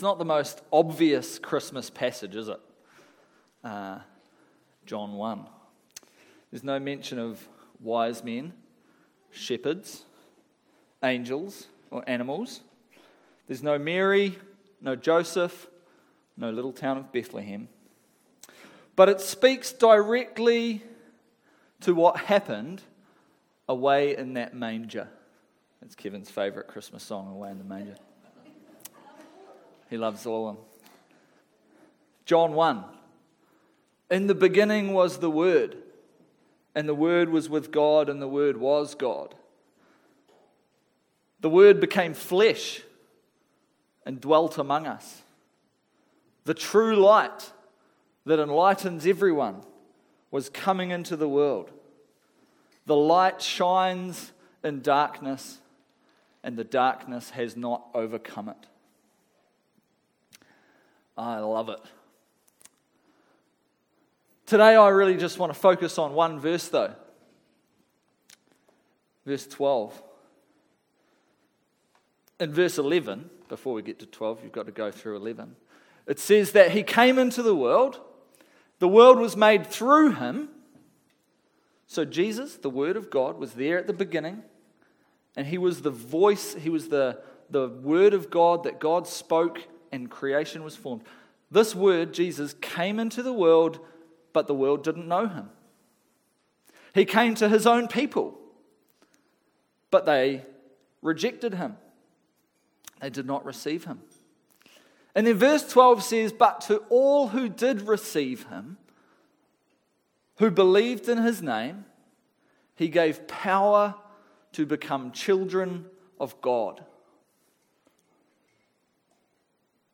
Service Type: Family Service